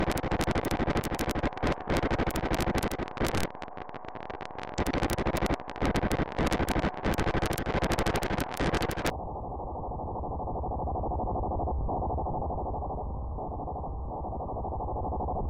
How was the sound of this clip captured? Recorded in NFMNarrowband Frequency Modulation and RAW.